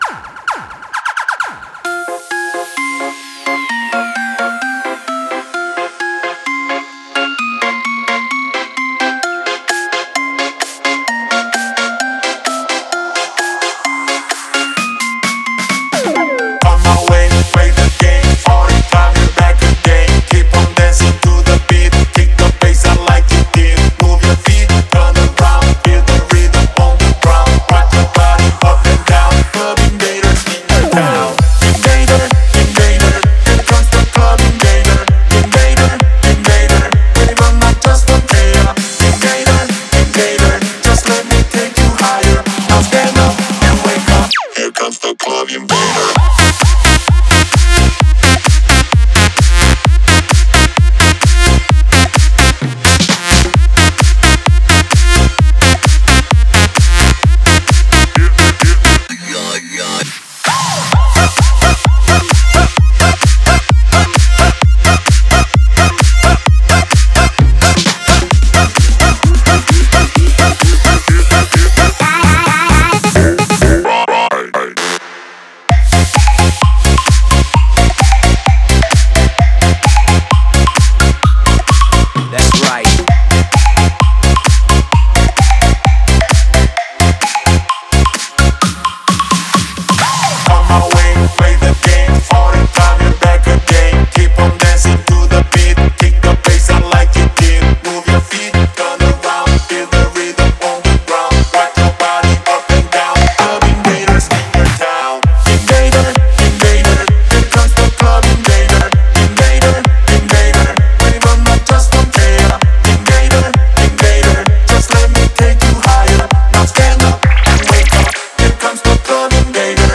Энергичная такая, веселенькая вещица